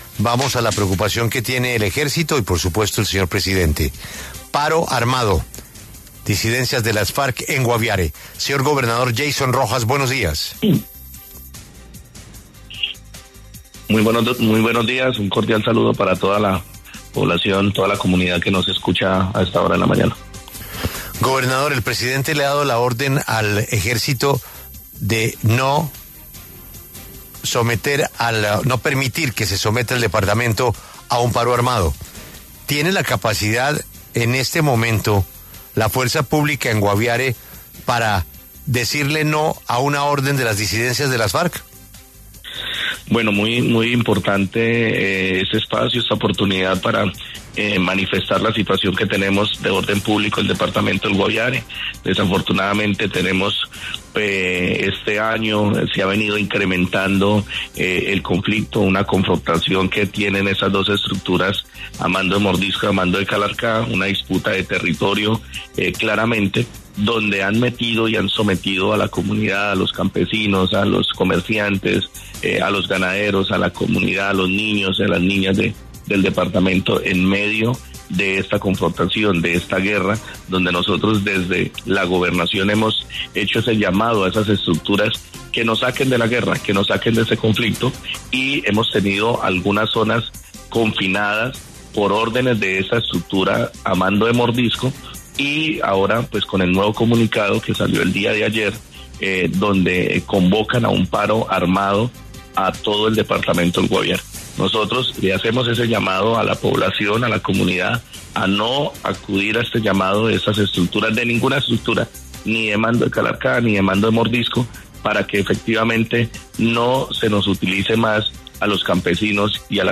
En entrevista con La W Radio, Rojas afirmó que la confrontación entre estas estructuras ha involucrado a comunidades campesinas, comerciantes, ganaderos y menores de edad.